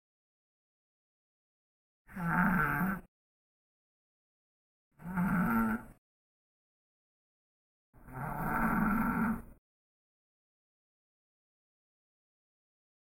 猫咪的呼噜声
描述：猫对着话筒打呼噜的声音。
Tag: 动物 快乐 杂音 咕噜咕噜叫